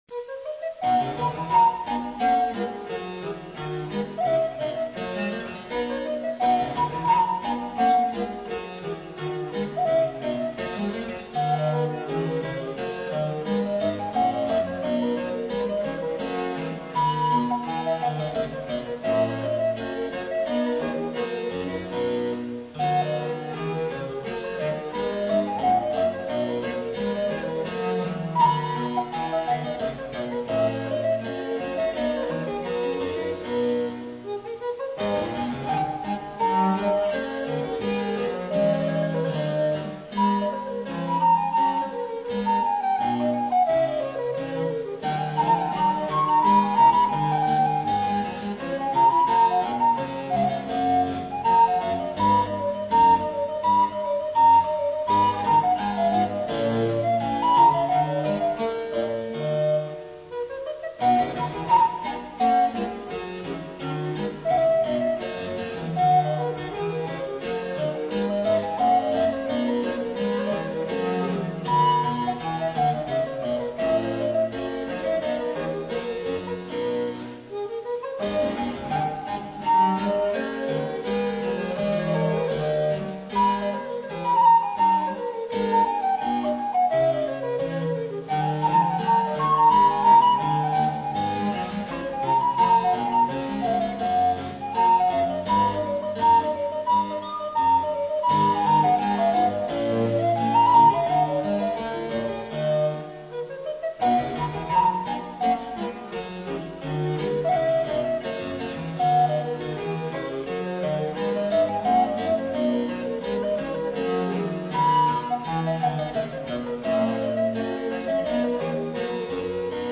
Händel : extrait de la sonate en do majeur pour flûte alto et basse continue (ici, le clavecin).